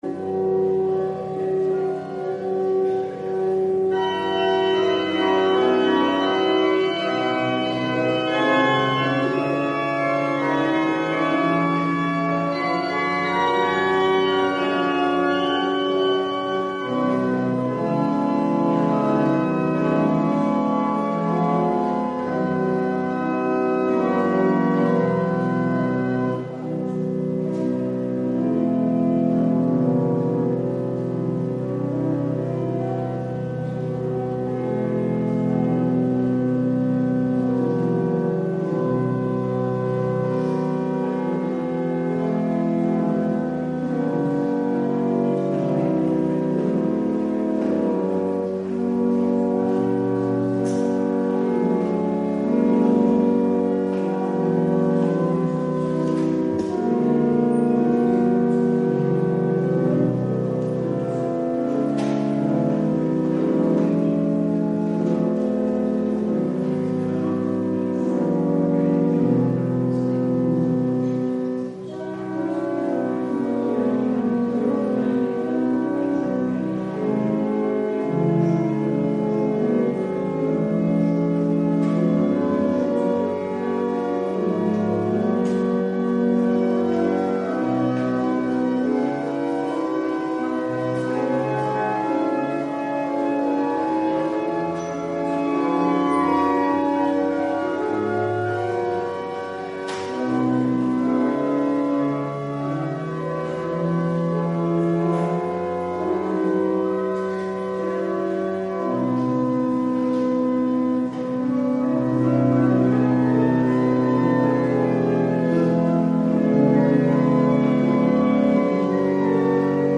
Doopdienst beluisteren Orde van de dienst op 3 april 2011 in de Dorpskerk te Nijbroek Bijbehorende orde van